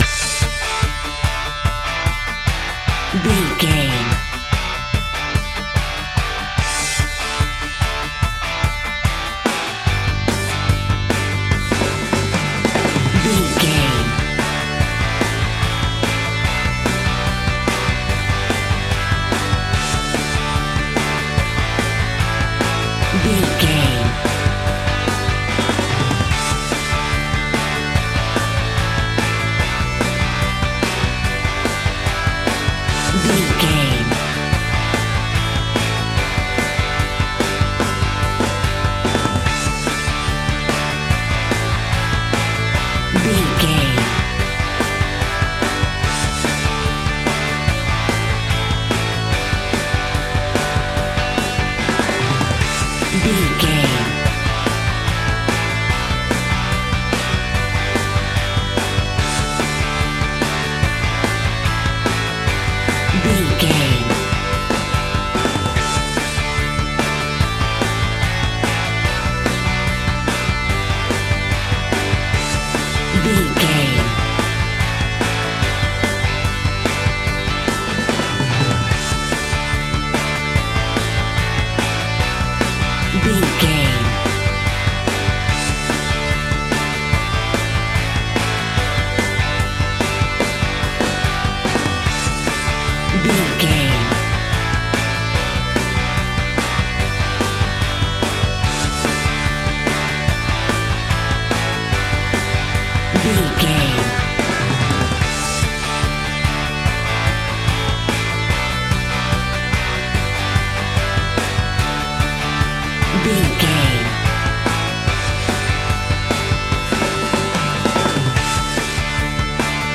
Uplifting
Ionian/Major
D
cheerful/happy
dreamy
serene